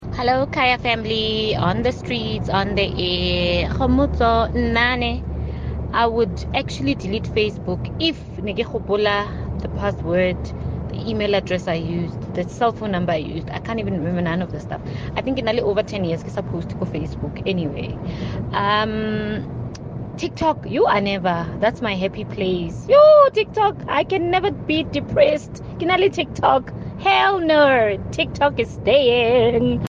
Midday Joy listeners share which social media apps need to go: